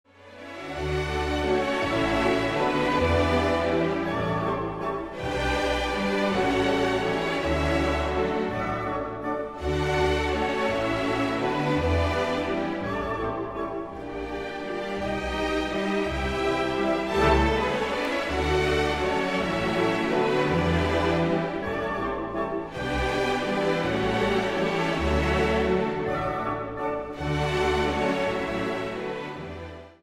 классические